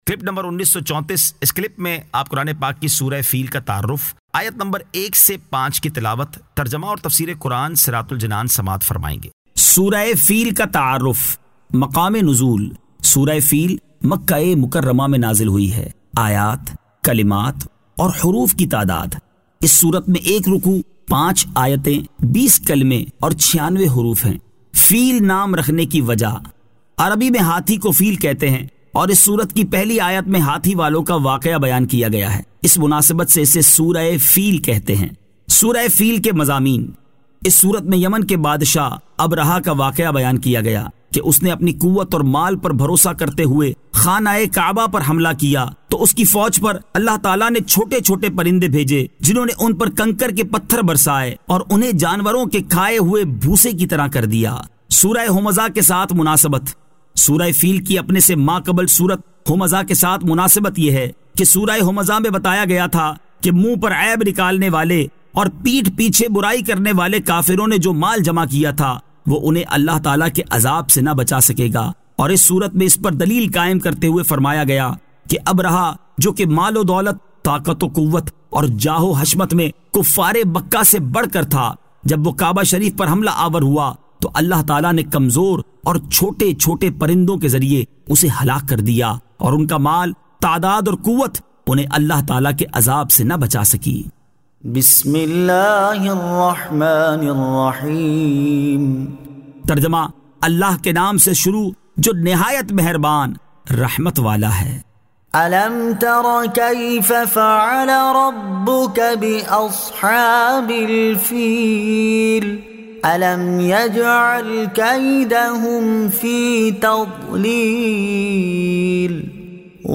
Surah Al-Feel 01 To 05 Tilawat , Tarjama , Tafseer